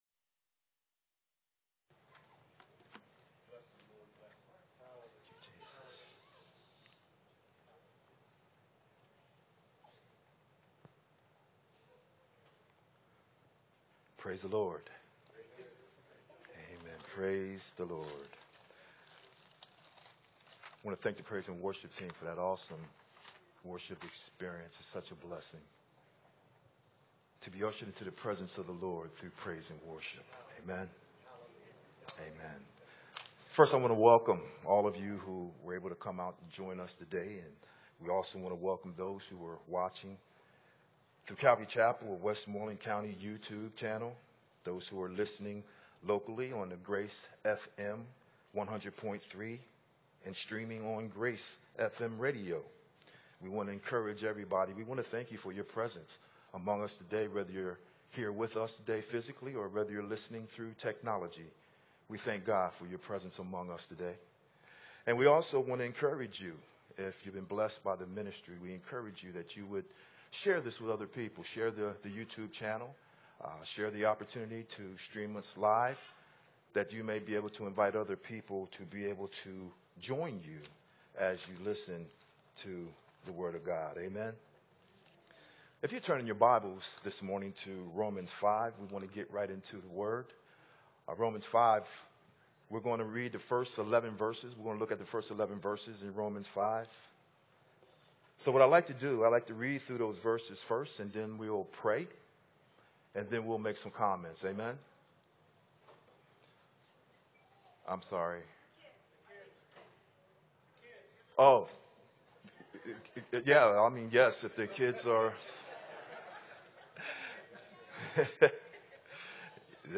Sermon starts at the 18 minute mark in the video…